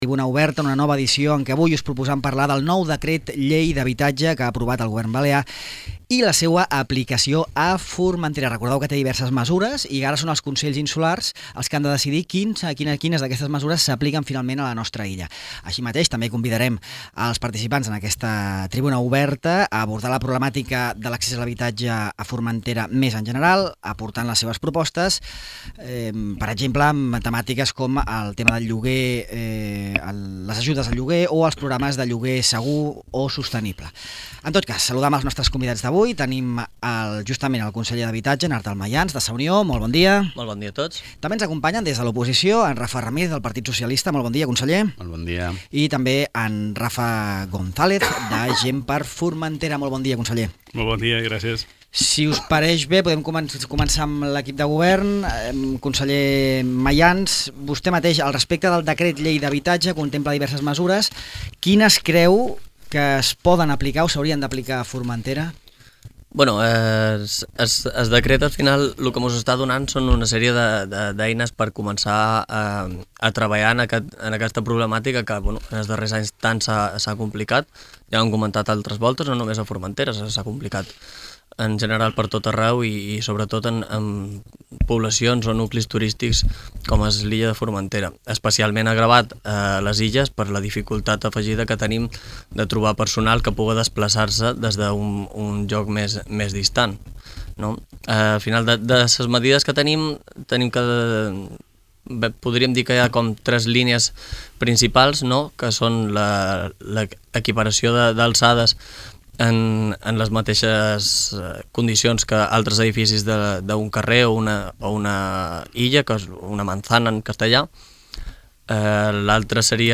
El nou Decret Llei d'habitatge a Formentera, a la tertúlia política
Rafa Ramírez, conseller del PSOE; Rafa González, conseller de Gent per Formentera; i Artal Mayans, conseller d’Habitatge de Sa Unió participen en una nova edició de la Tribuna Oberta dedicada a debatre sobre el nou Decret Llei de mesures urgents en matèria d’habitatge i la seva aplicació a Formentera.